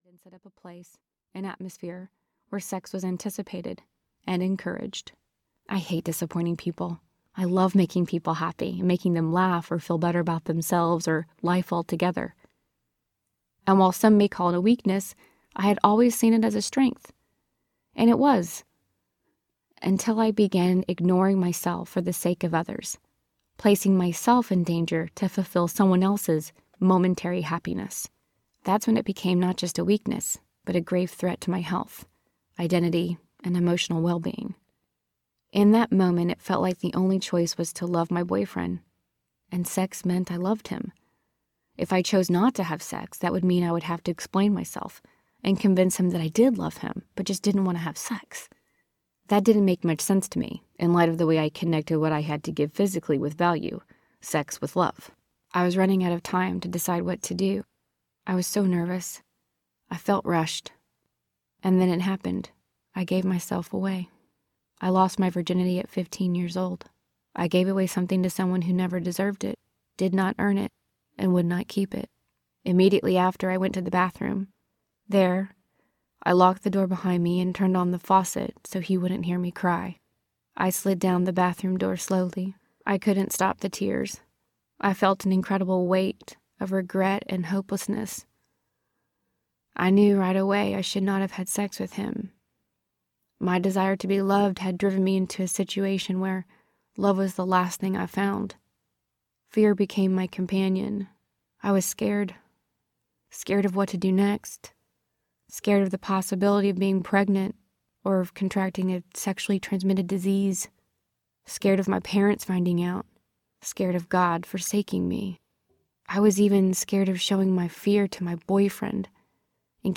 The Other Side of Beauty Audiobook
Narrator